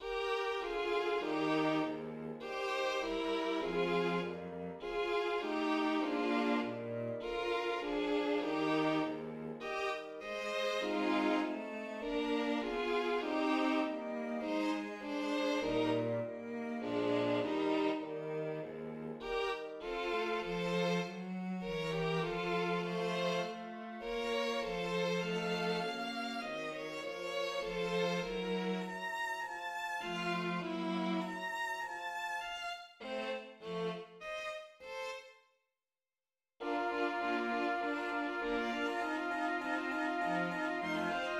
Andante
Il secondo movimento, Andante, è in Mi bemolle maggiore e in tempo 2/4. Il movimento inizia con gli archi in sordina. Contrasta con l'Allegro di apertura per la sua atmosfera riservata e calma, con dinamica in piano. Il primo tema è costituito da tre crome discendenti, che si scambiano in un dialogo fra le voci acute assegnate ai violini e quella grave realizzata dal fagotto, e dalla misura 5 anche dai bassi.[1][4] Di seguito, l'incipit del movimento: